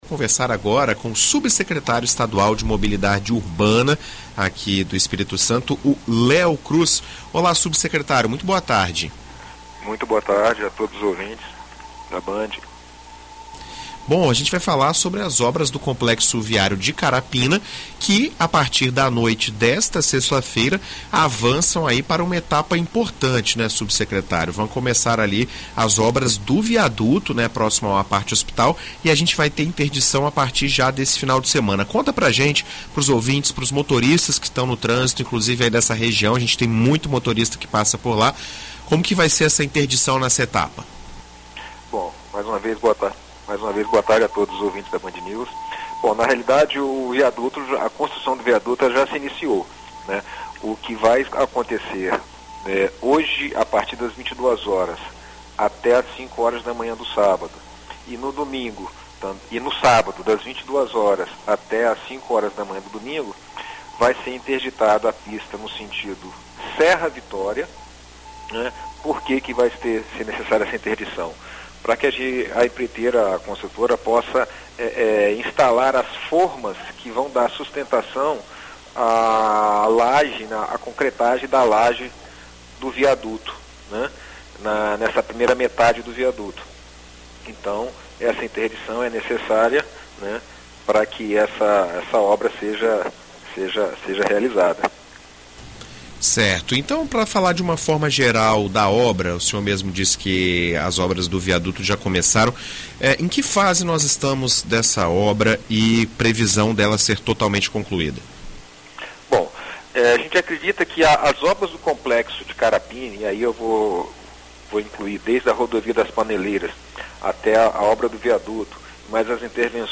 Em entrevista à BandNews FM ES nesta sexta-feira (24), O subsecretário estadual de mobilidade urbana, Leo Cruz, fala sobre as obras do complexo viário de Carapina e interdição no trânsito nas noites e madrugadas neste fim de semana.